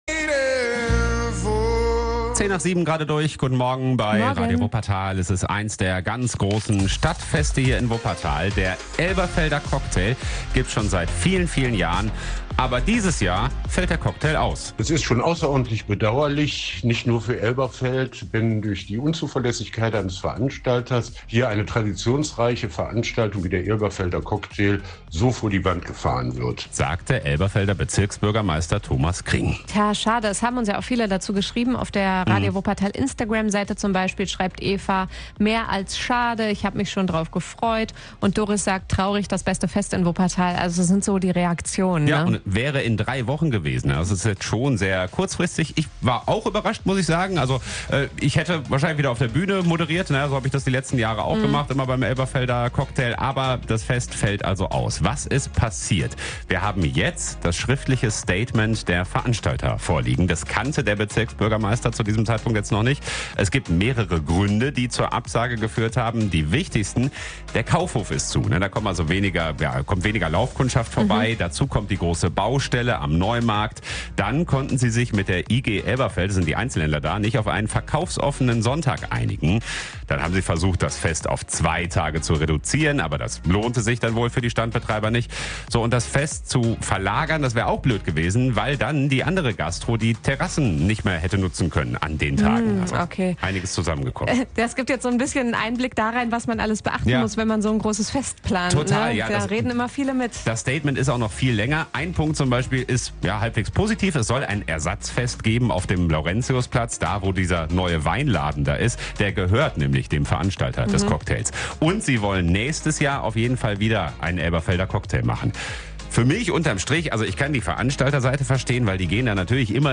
Das sagt der Bezirksbürgermeister Thomas Kring im Radio-Wuppertal-Interview.